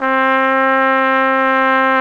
Index of /90_sSampleCDs/Roland L-CD702/VOL-2/BRS_Tpt 5-7 Solo/BRS_Tp 6 AKG Jaz